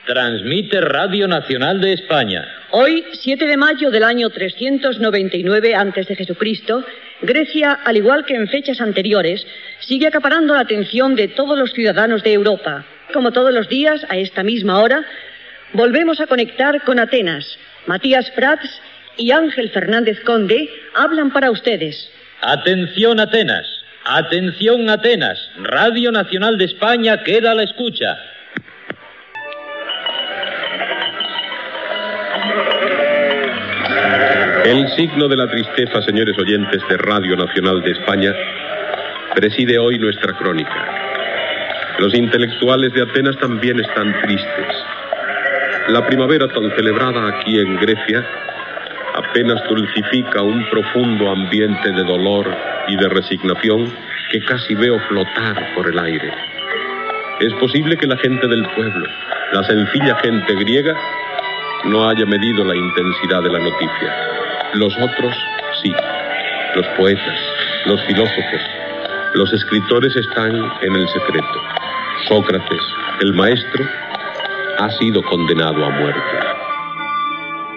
Ficció
Espai dedicat a passatges de la història, recreats com si la ràdio hi fos present.